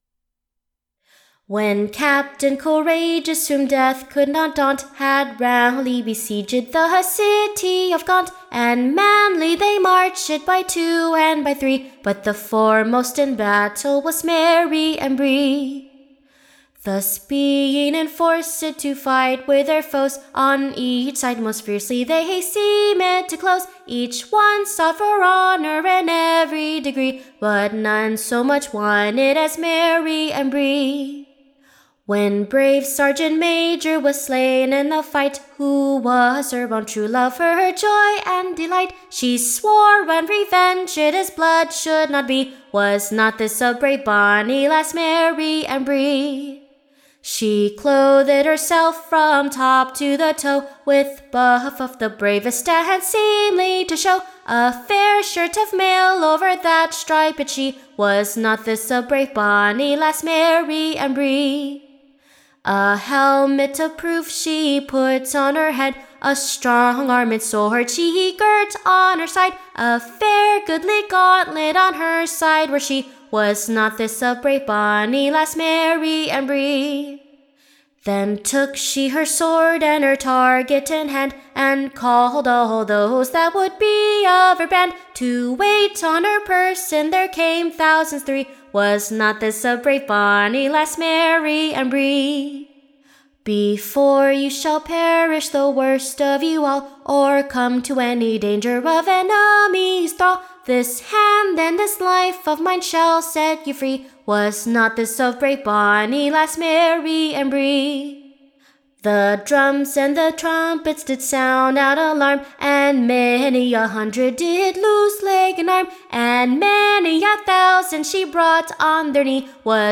Recording Information Ballad Title The valorous Acts performed at Gaunt, / By the brave bonny Lasse Mary Ambre; Who in revenge of her / Lovers death, did play her part most gallantly. Tune Imprint To the tune of, the Blind beggar, Standard Tune Title Blind Beggar of Bethnal Green Media Listen 00 : 00 | 13 : 54 Download c1.1288.mp3 (Right click, Save As)